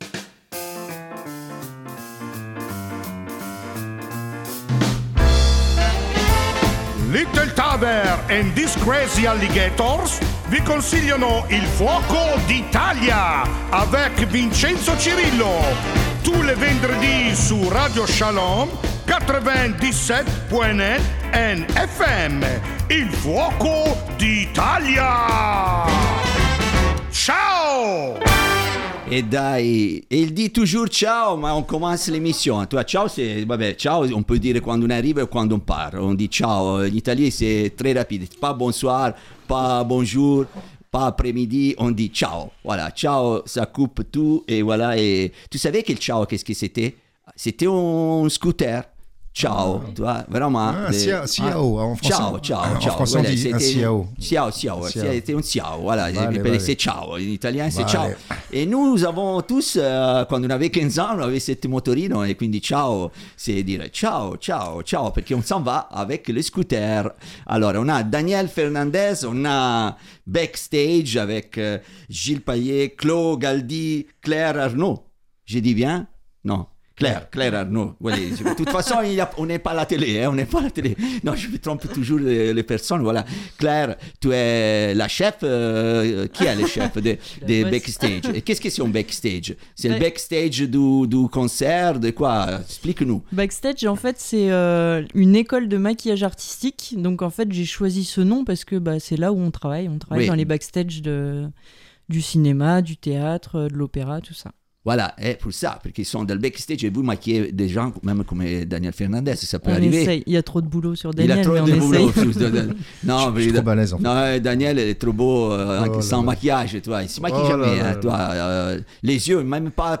Encore une belle journée en compagnie de nos invités =)